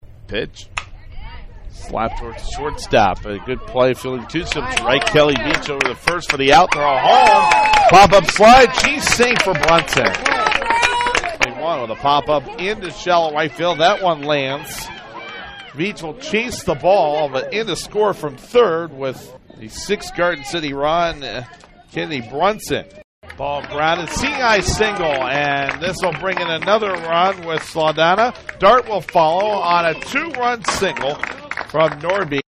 Highlights Game Two